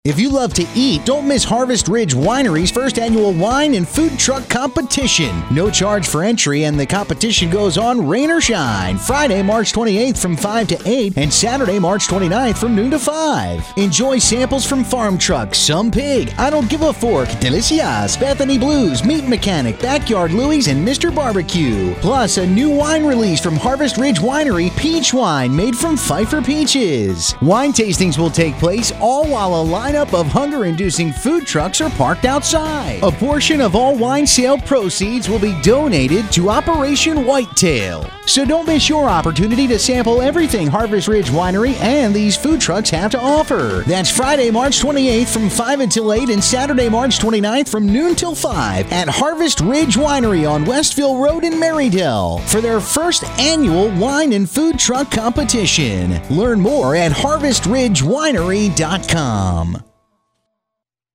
You may have heard the commercial for the food truck competition this weekend.
Harvest-Ridge-Winery_Food-Truck-Competition.mp3